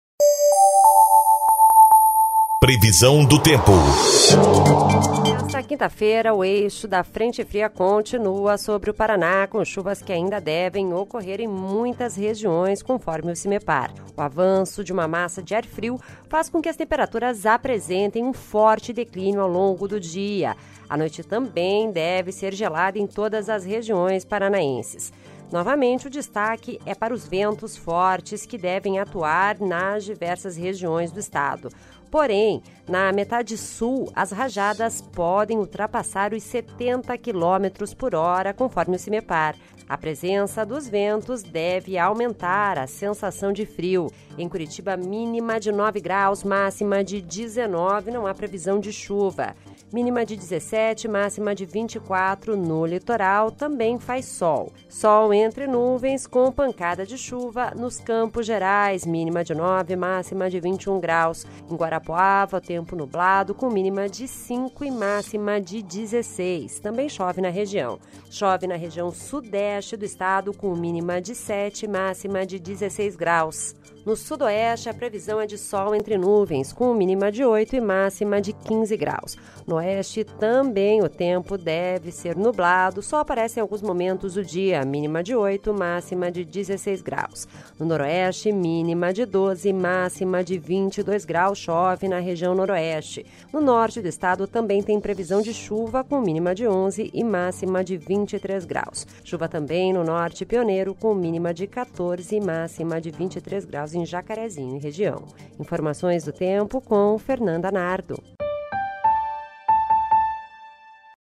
Previsão do Tempo (13/07)